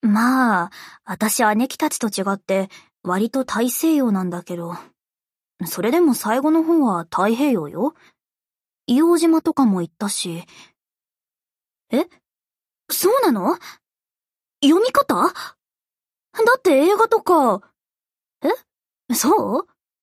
Ship Voice Tuscaloosa Idle.mp3